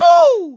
Mario pretends to spot a Boo, to distract the player. From Mario Golf: Toadstool Tour.
Mario_(Boo!)_-_Mario_Golf_Toadstool_Tour.oga